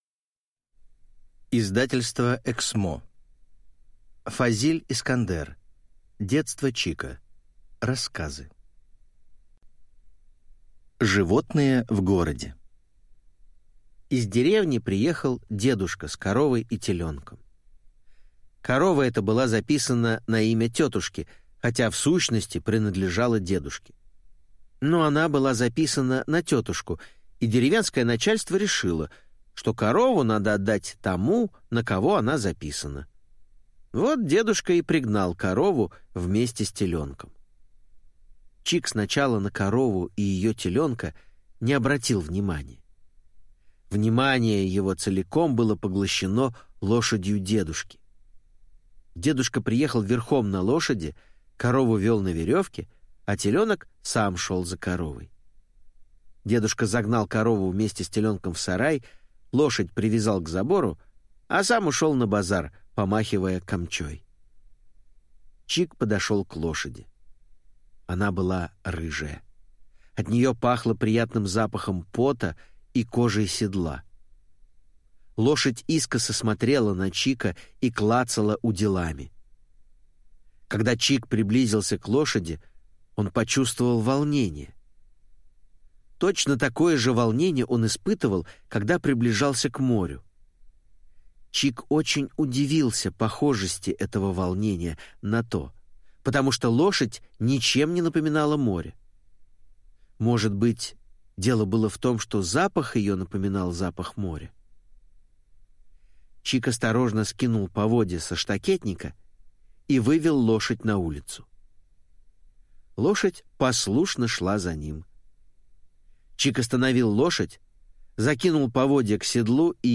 Аудиокнига Детство Чика | Библиотека аудиокниг